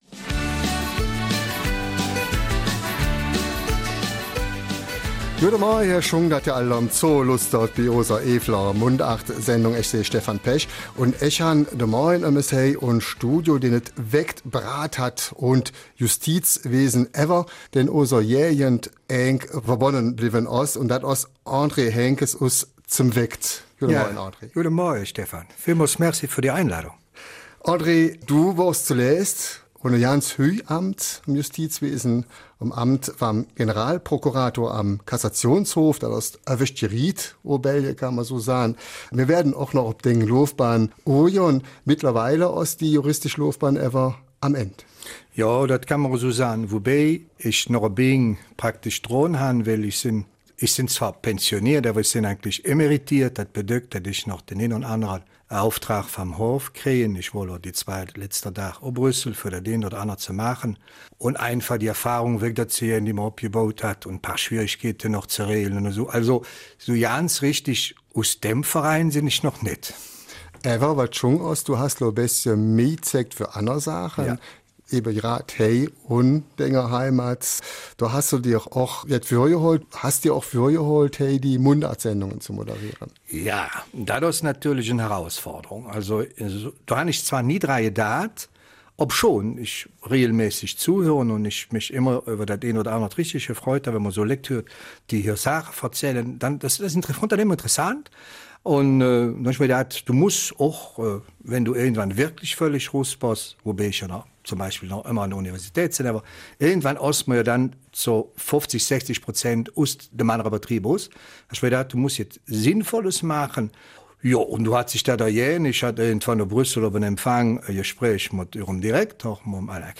Eifeler Mundart - 19. Januar